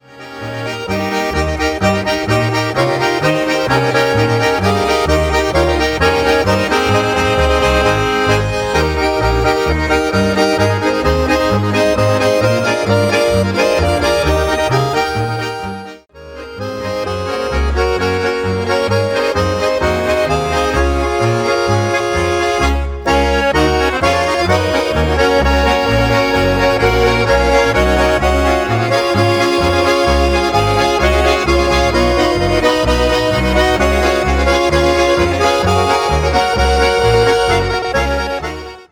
Schnellpolka